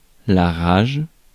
Ääntäminen
Synonyymit colère tristesse colère bleue Ääntäminen France: IPA: [ʁaʒ] Haettu sana löytyi näillä lähdekielillä: ranska Käännös Konteksti Ääninäyte Substantiivit 1. rabies lääketiede 2. rage US 3. anger US 4. outrage US Suku: f .